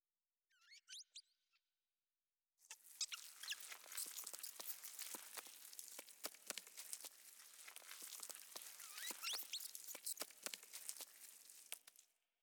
老鼠.wav